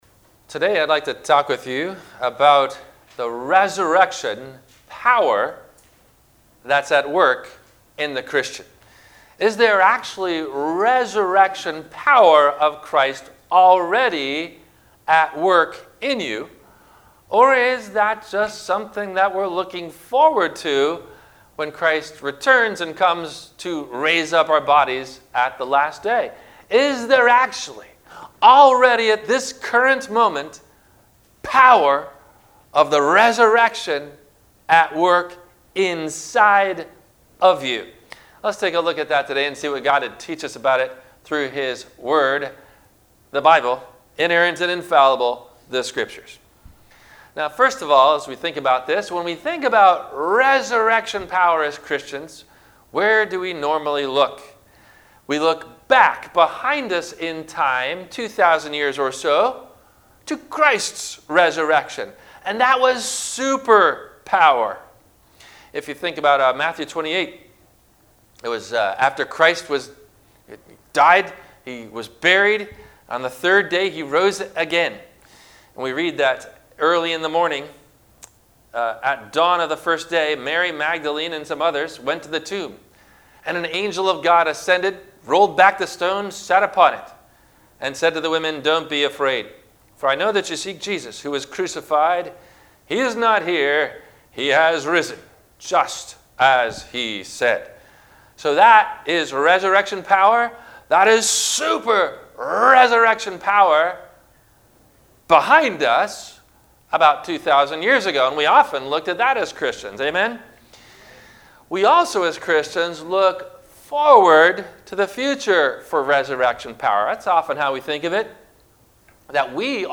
- Sermon - April 25 2021 - Christ Lutheran Cape Canaveral